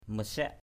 /mə-siak/